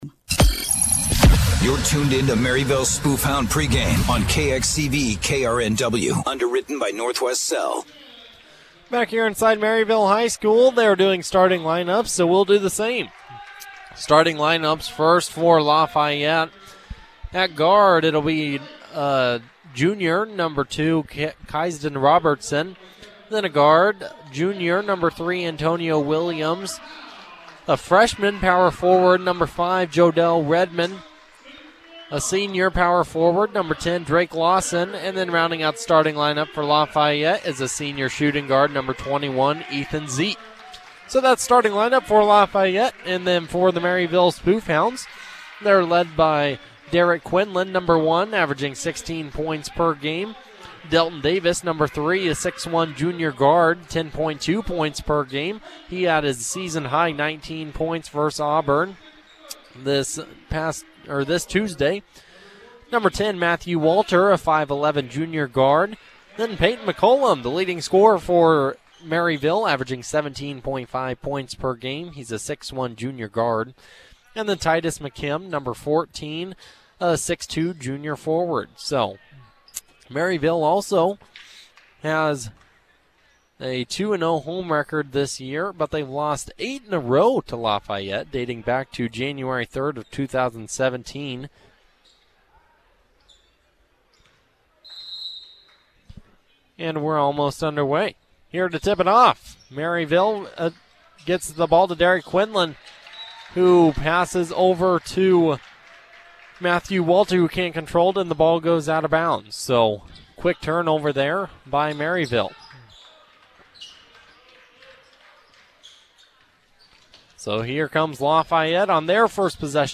Local Sports
Jan. 4, 2024Game | Spoofhound Basketball